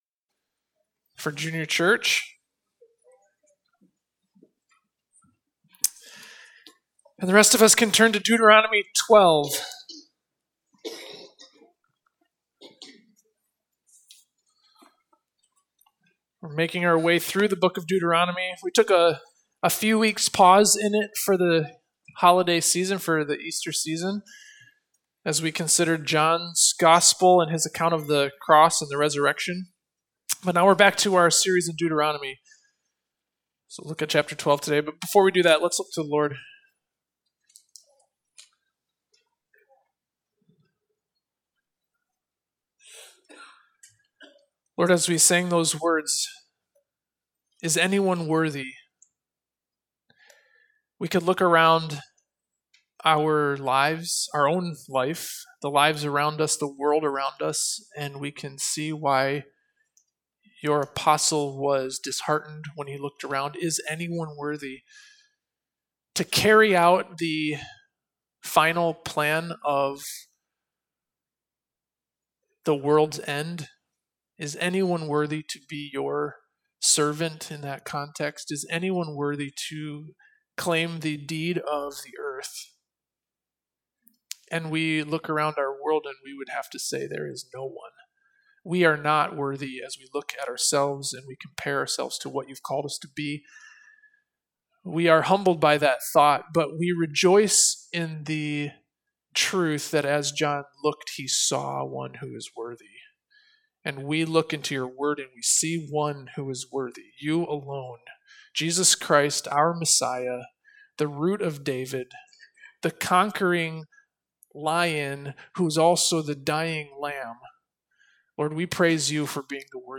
Sermons :: Faith Baptist Church